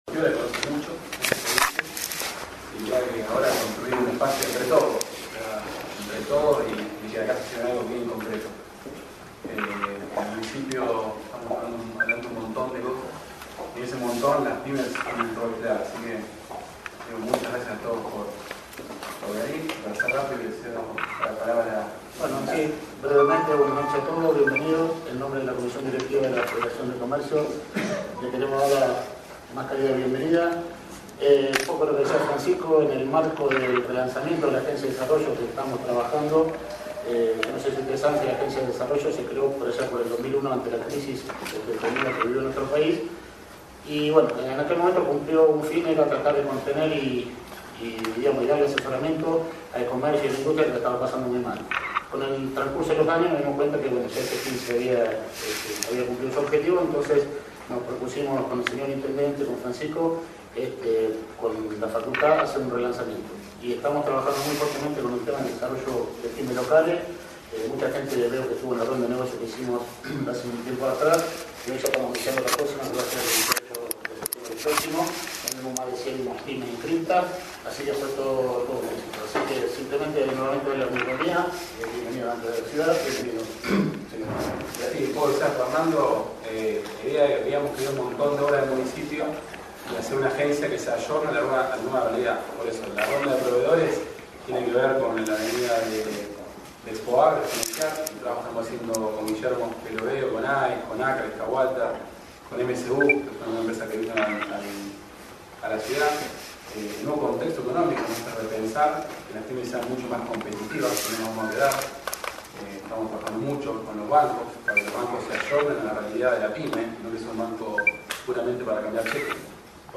Audio: Conferencia de Prensa